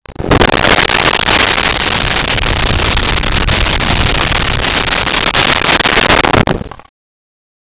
Creature Breath 01
Creature Breath 01.wav